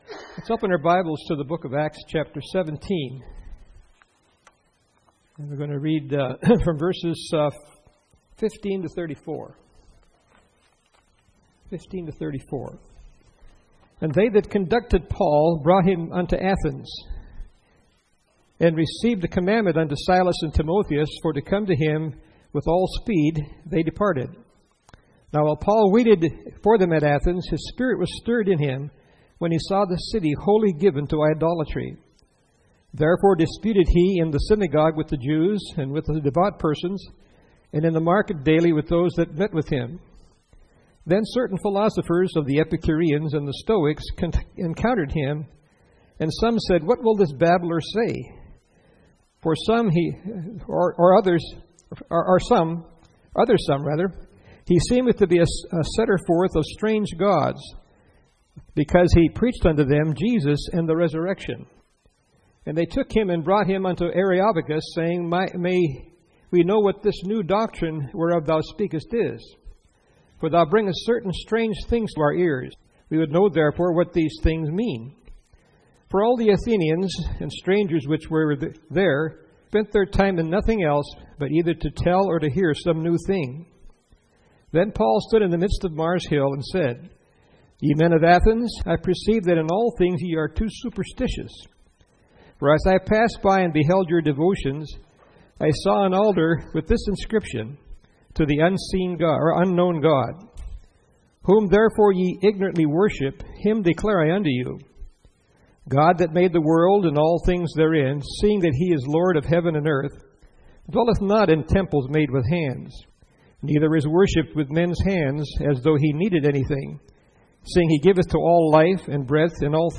Series: 2013 August Conference
Session: Morning Session